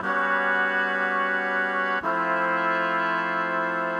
Index of /musicradar/gangster-sting-samples/120bpm Loops
GS_MuteHorn_120-G.wav